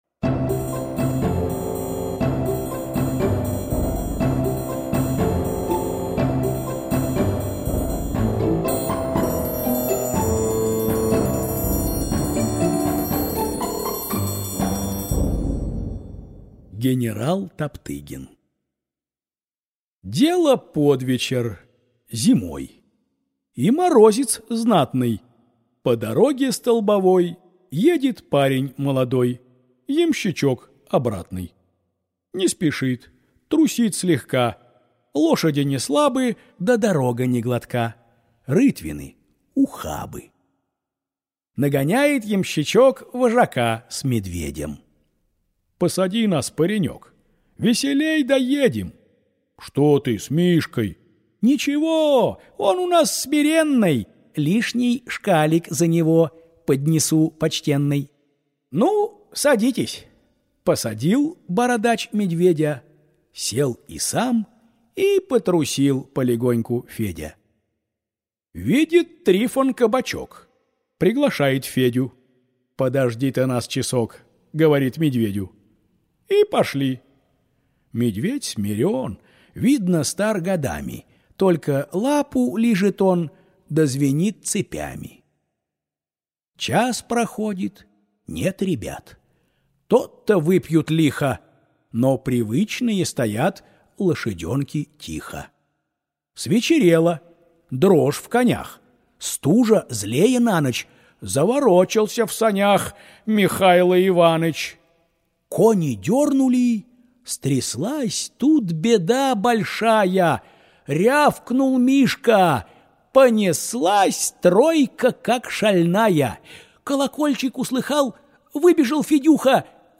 На данной странице вы можете слушать онлайн бесплатно и скачать аудиокнигу "Генерал Топтыгин" писателя Николай Некрасов.